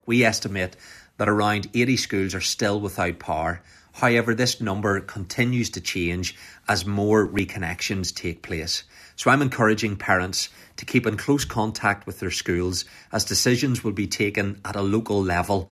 The North’s Education Minister Paul Givan says some schools will remain closed today because of storm damage……………